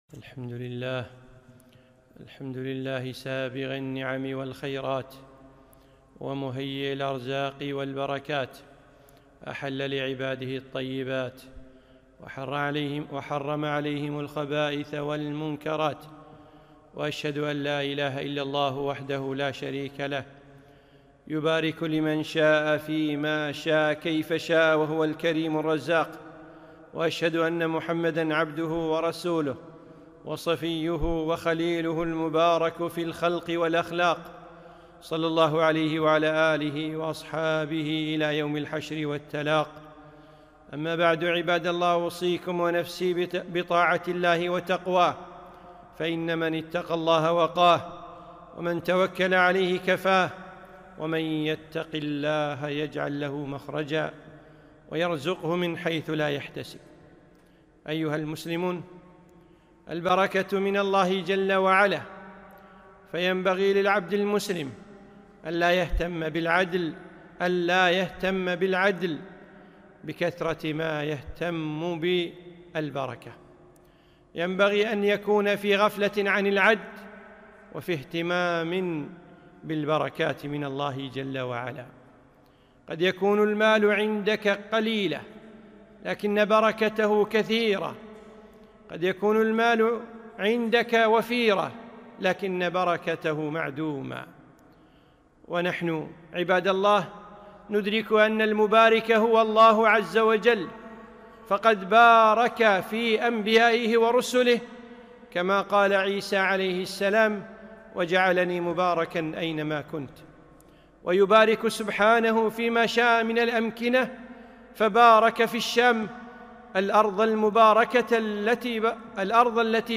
خطبة - البركة من الله